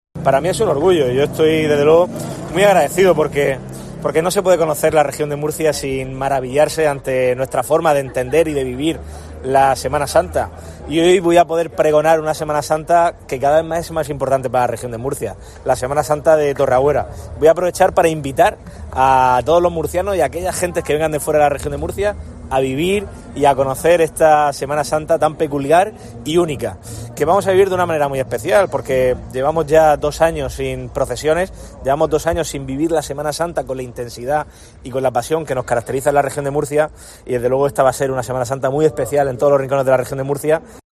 El presidente pronunció el pregón de la Semana Santa de la pedanía murciana de Torreagüera,
Durante el pregón, que pronunció en la iglesia parroquial Santísimo Cristo del Valle de Torreagüera, el presidente de la Región de Murcia se refirió al solemne Vía Crucis que protagonizará la imagen del Santísimo Cristo del Valle y el besamanos a la Virgen de los Dolores "como prólogo y anuncio de que la Semana Santa ya está aquí".